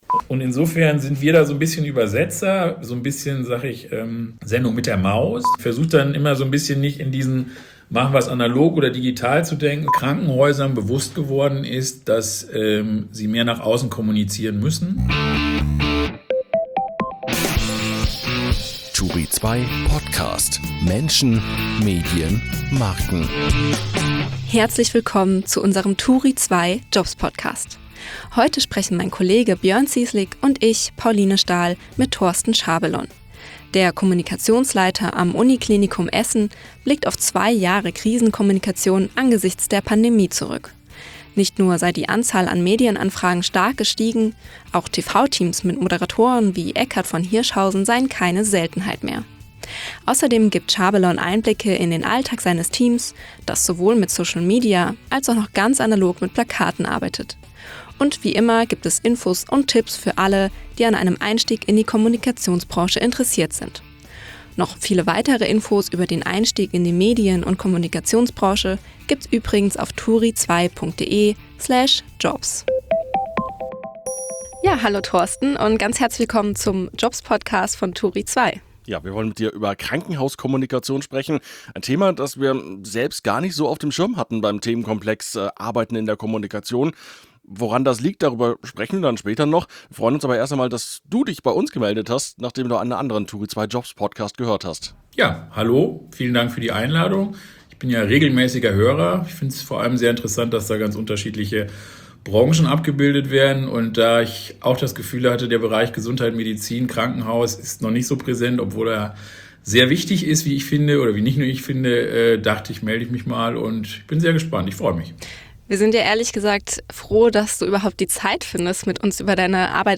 Im turi2 podcast erzählen Medien-, Marken- und Meinungsmacher*innen, wie sie die Herausforderungen der Digitalisierung angehen und was sie persönlich antreibt.